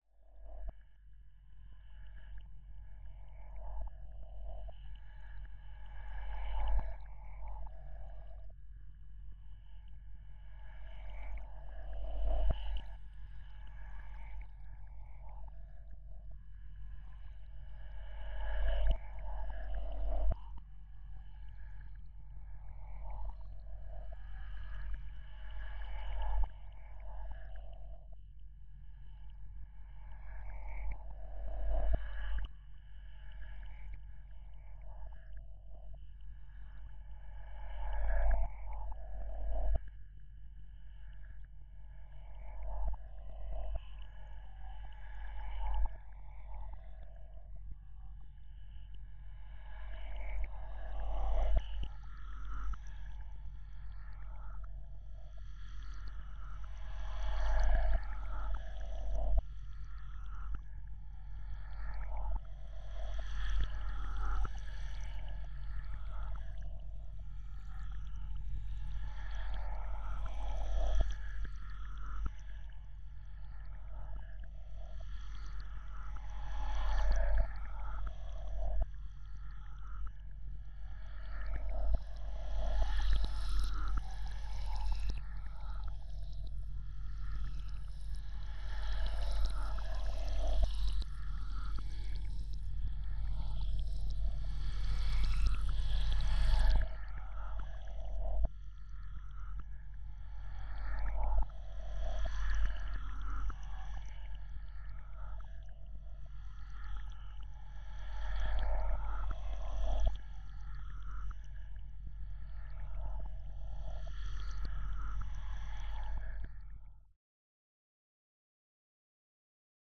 drones